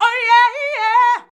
OHYEAHIY 3.wav